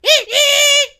P先生只会像一只企鹅发出无意义的叫声，但是可以从中听出情绪。
Media:mrp_kill_vo_02.ogg Mr. P laughs
P先生的笑声